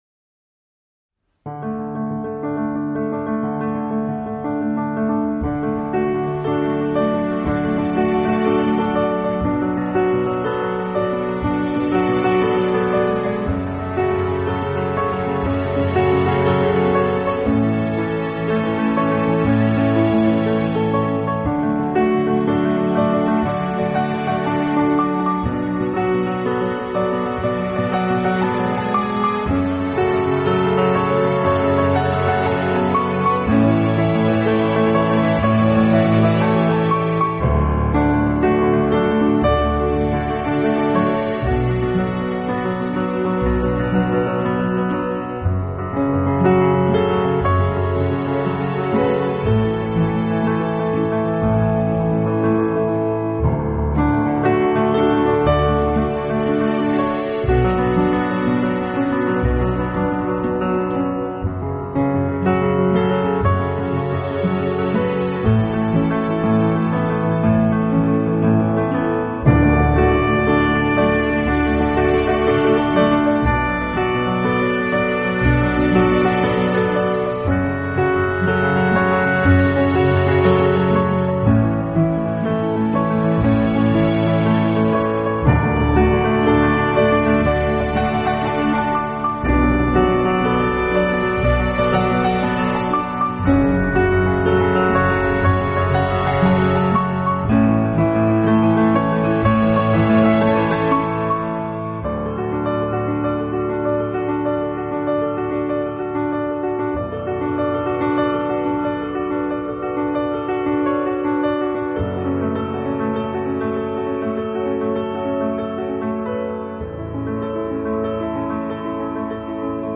【新世纪钢琴】
音乐流派：New Age，Piano Solo